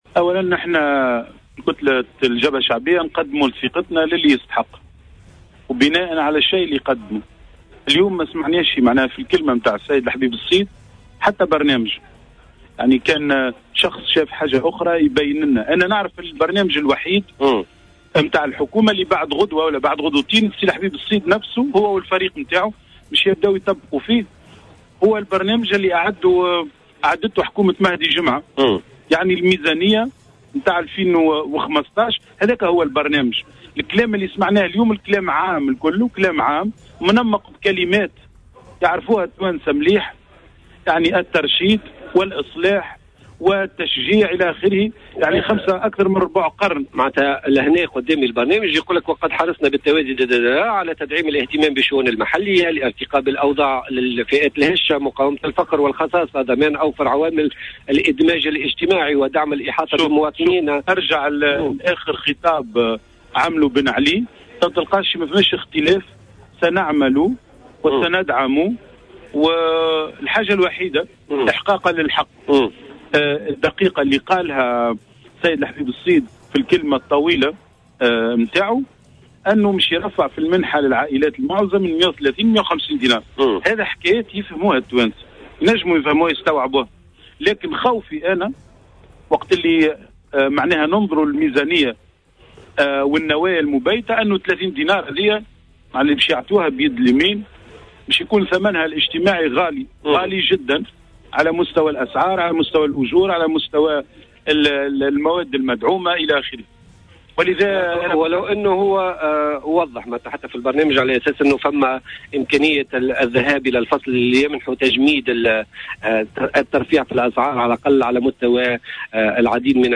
Fethi Chamkhi, le président du groupe parlementaire du Front Populaire, a fustigé au micro de Jawhara Fm le discours d'Habib Essid qu'il a donné devant l'Assemblée des représentants du peuple à l'ouverture de la séance plénière consacrée au vote de confiance du nouveau gouvernement.